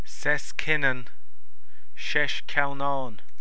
Audio File (.wav) Comhad Fuaime Foghraíochta